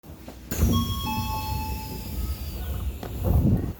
3000形 車載発車放送